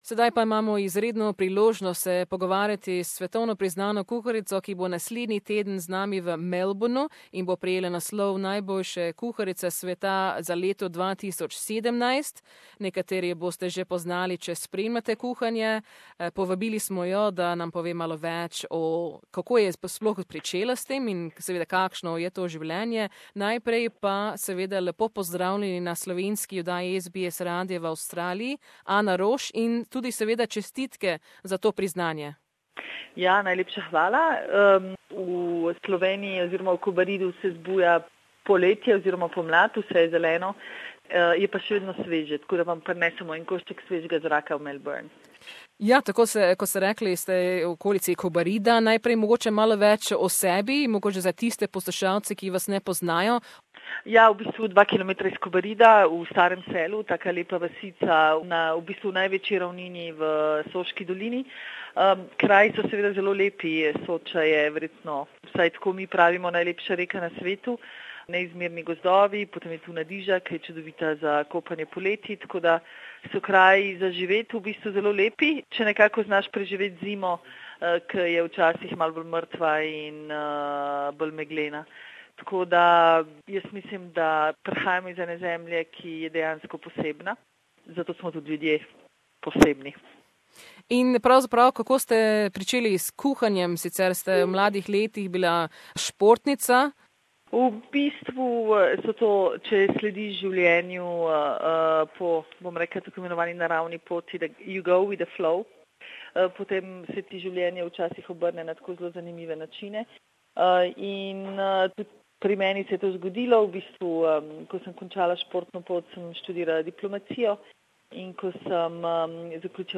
Slovenian Ana Roš will be in Melbourne next week where she will be presented with the title The World's Best Female Chef 2017. In this very interesting interview, she spoke about how she started on her culinary journey and what this award means to her and her restaurant 'Hiša Franko' in Kobarid, which this year is amongst the top 100 World's Best Restaurants.